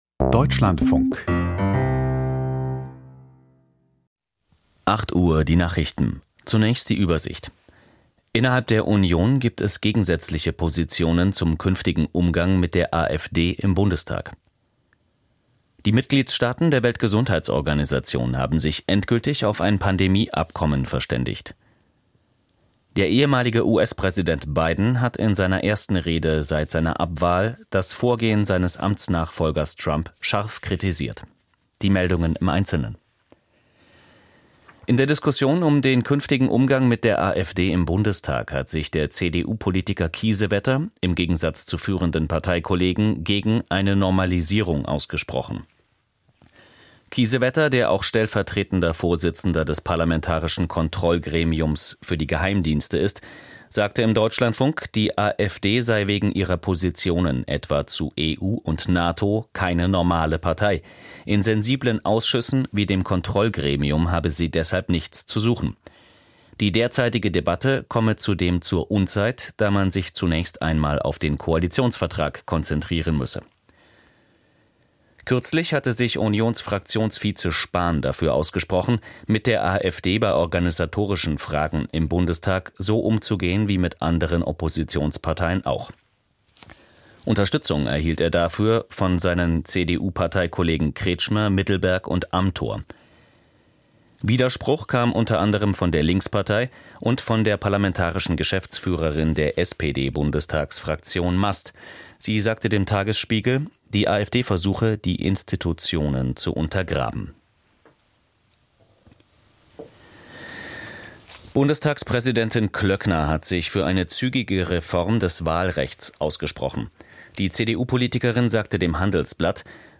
Die Deutschlandfunk-Nachrichten vom 16.04.2025, 08:00 Uhr